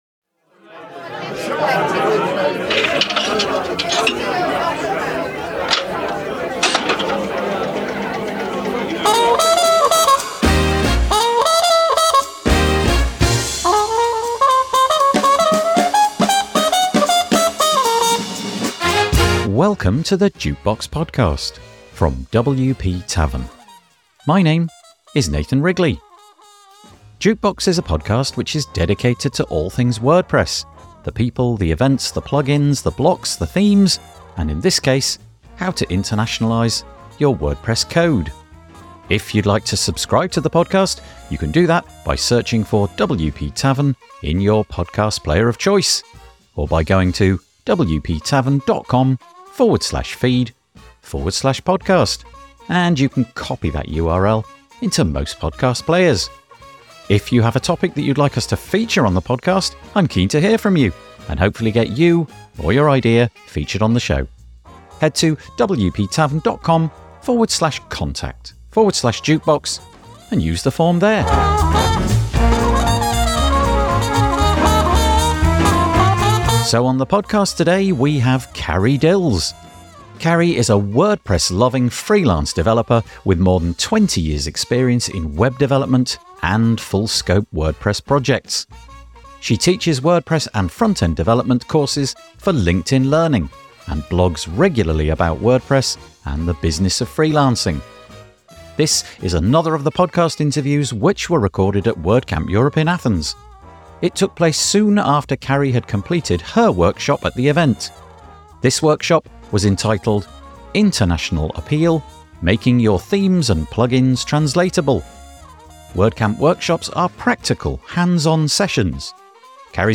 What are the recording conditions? This is another of the podcast interviews which were recorded at WordCamp Europe in Athens.